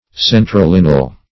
Search Result for " centrolineal" : The Collaborative International Dictionary of English v.0.48: Centrolineal \Cen`tro*lin"e*al\ (s[e^]n`tr[-o]*l[i^]n"[-e]*al), a. [L. centrum + linea line.] Converging to a center; -- applied to lines drawn so as to meet in a point or center.